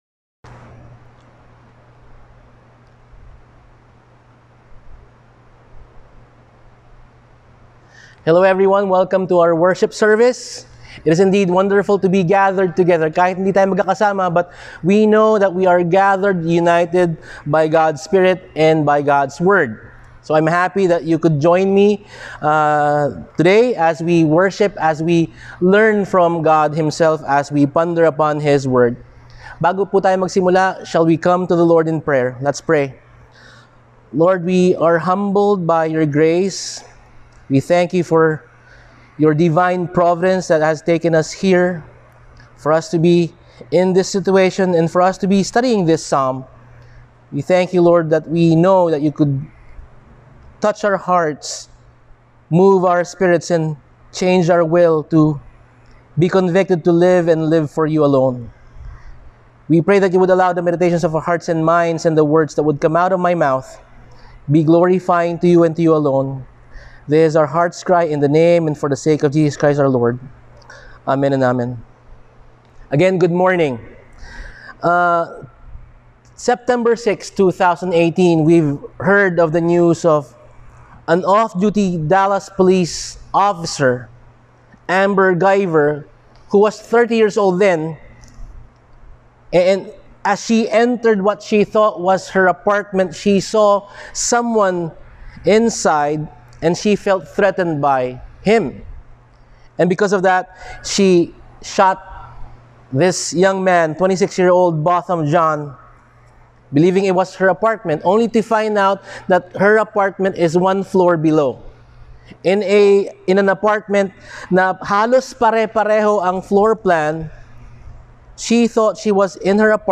Service: Sunday Sermon